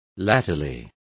Complete with pronunciation of the translation of latterly.